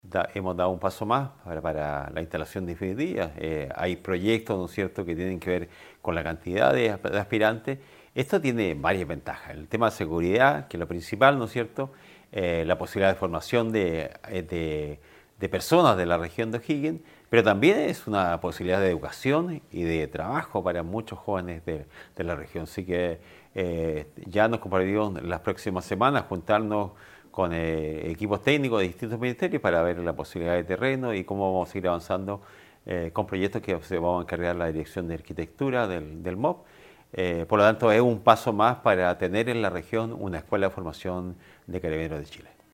Escuchemos la declaración del gobernador Silva Amaya: